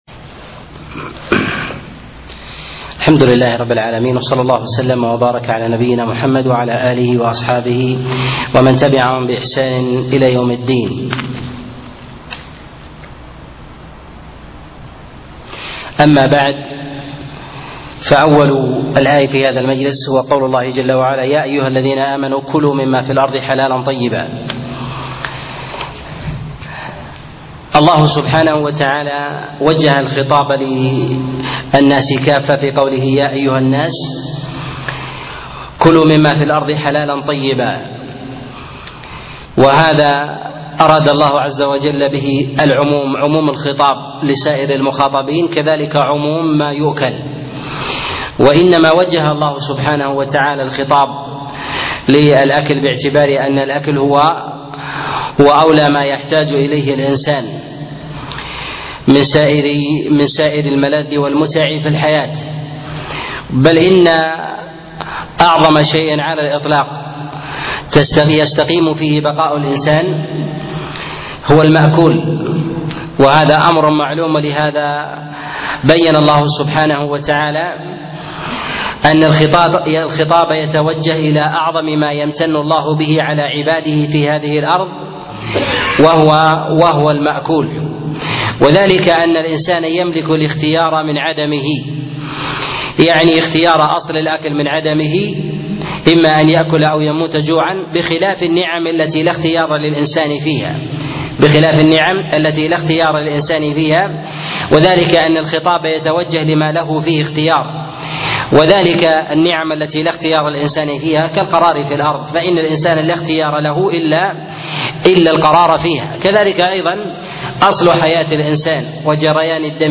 تفسير سورة البقرة 7 - تفسير آيات الأحكام - الدرس السابع